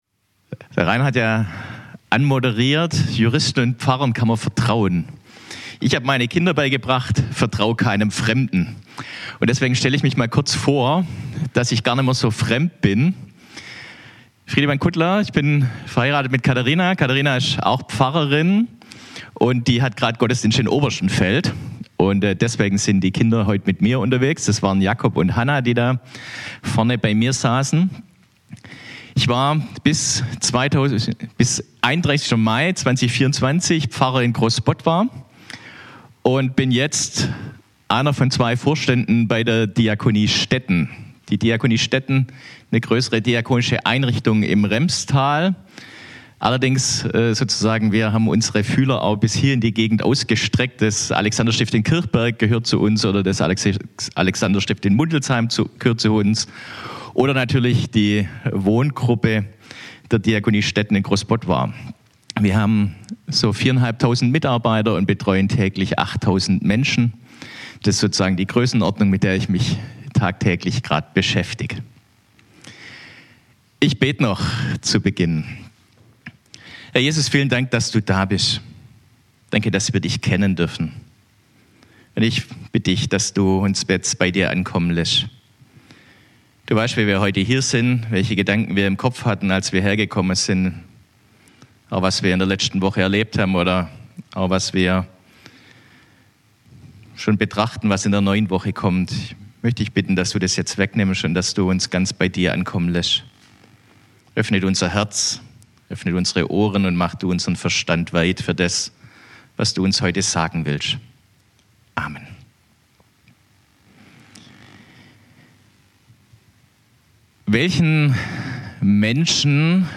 Crossline-Predigt vom 10.03.2024